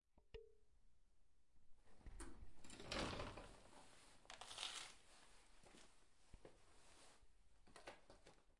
14 从摩托车上下来